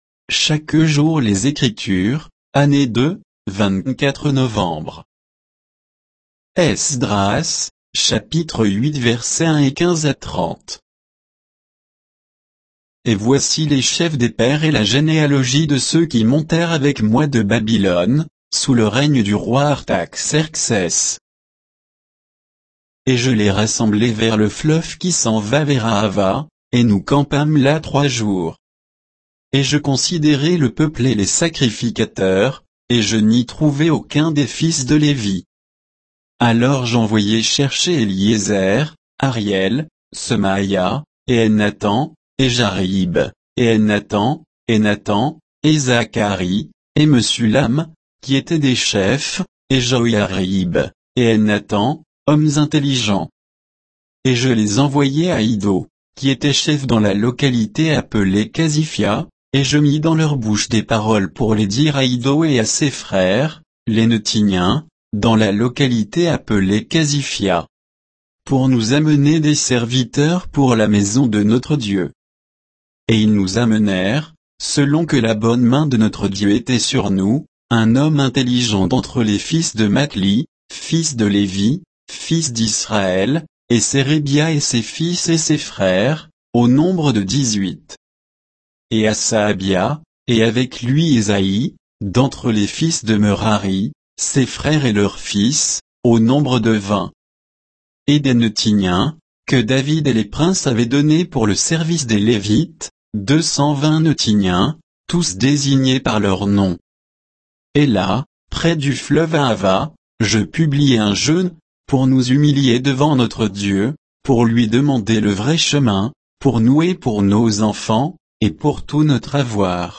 Méditation quoditienne de Chaque jour les Écritures sur Esdras 8, 1, 15 à 30